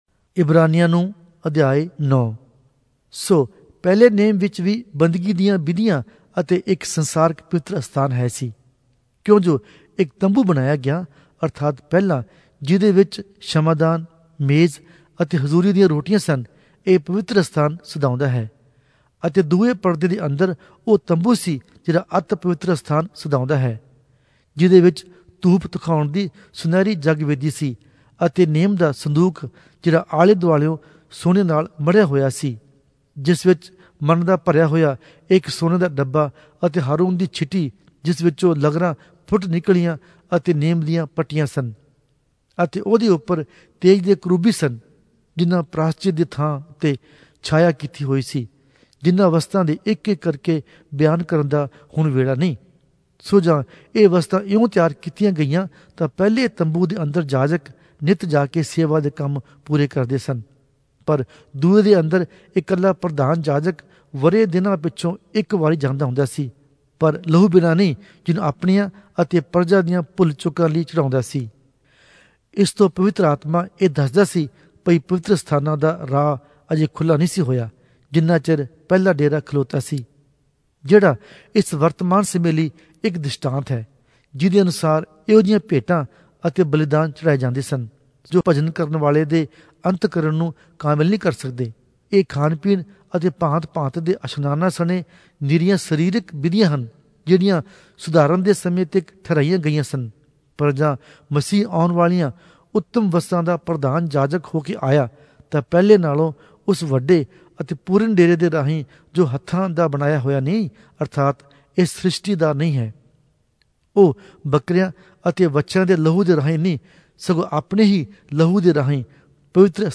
Punjabi Audio Bible - Hebrews 5 in Nlv bible version